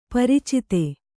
♪ paricite